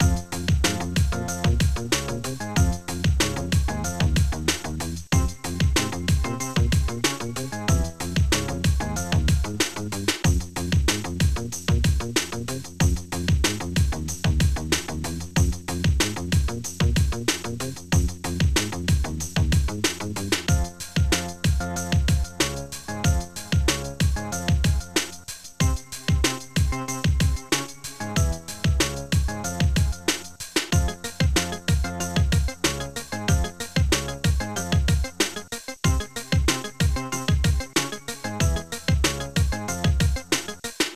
Star Tracker/StarTrekker Module
2 channels